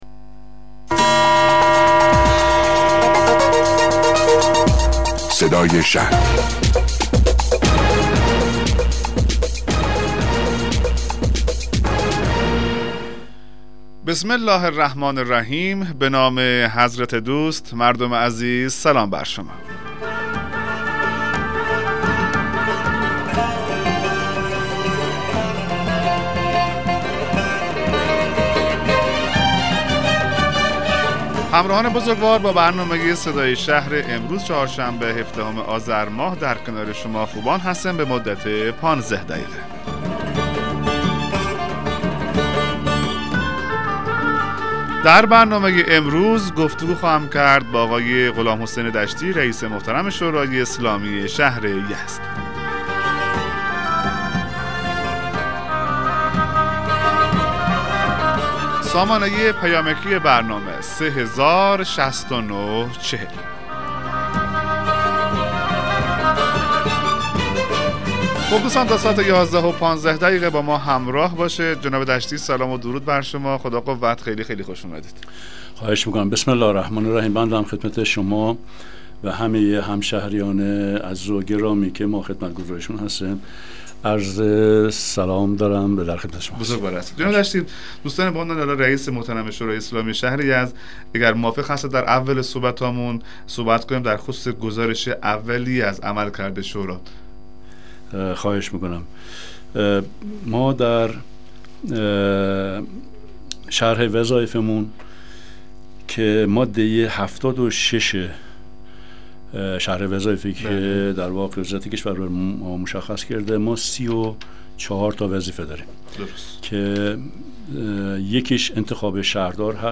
مصاحبه رادیویی برنامه صدای شهر با حضور غلامحسین دشتی رییس شورای اسلامی شهر یزد